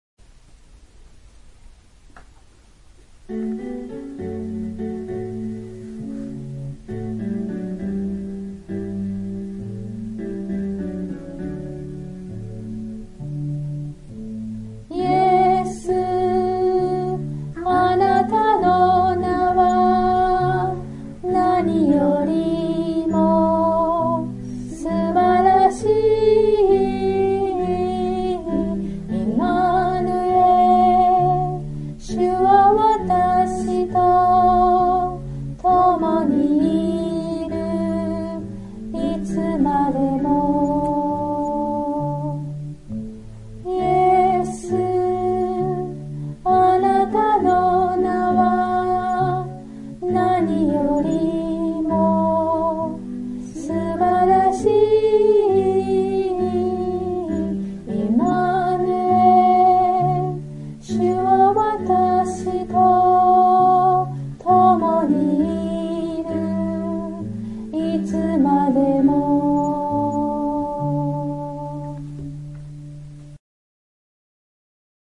（徳島聖書キリスト集会集会員）